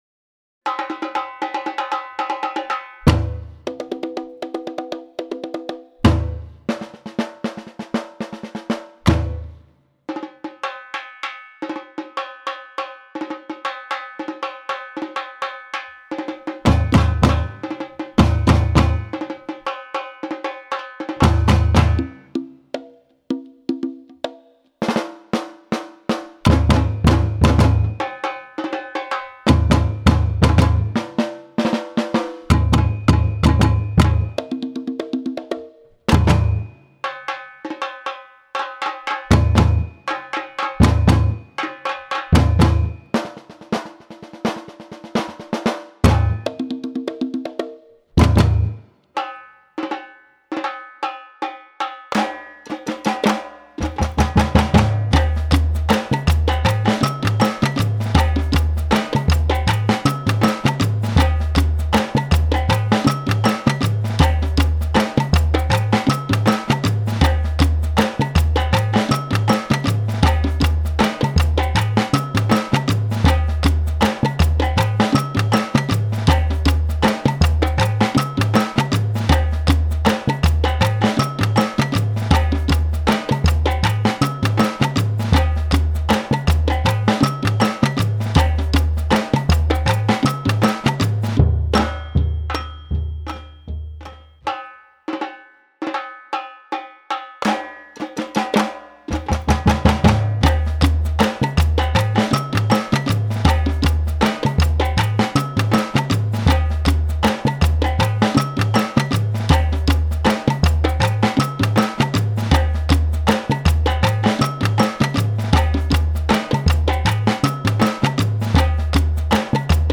(Lows = surdos; Highs = everyone else)
afro_funk_simple.mp3